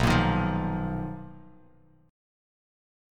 Listen to BM#11 strummed